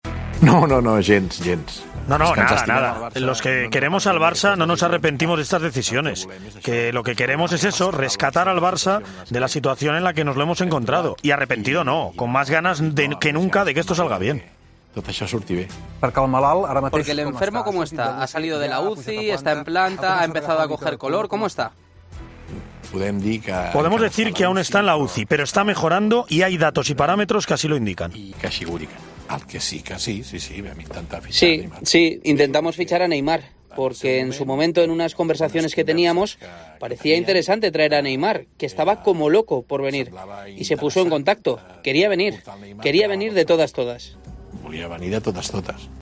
AUDIO: El presidente del Barcelona repasa la actualidad del conjunto azulgrana en una entrevista concedida al programa 'Onze' de Esport 3.